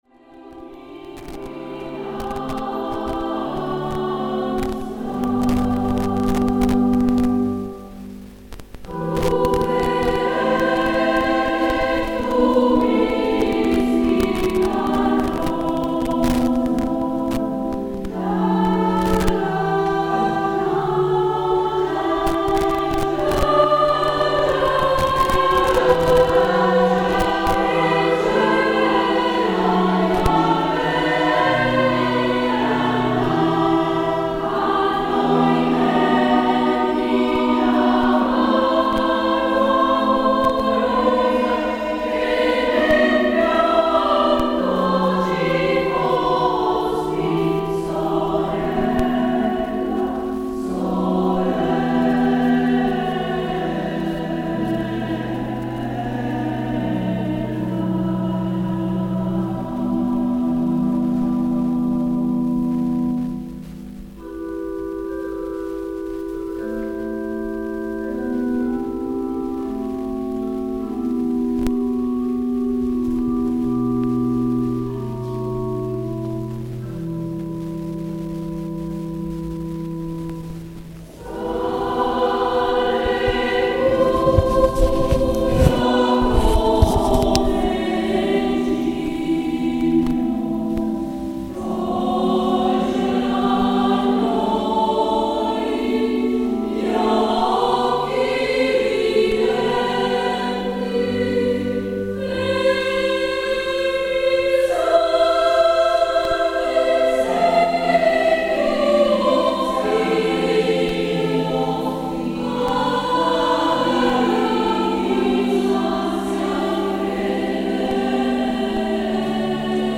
BARGA - Chiesa di San Francesco - Concerto Vocale e strumentale Ore 21.15
a short MP3 file of the concert can be heard
Concerto Vocale e Strumentale
Chiesa di San Francesco
La Corale femminile del Duomo di Barga
mezzasoprano
Oboe
Organo e Clavicembalo
corale_di_barga.mp3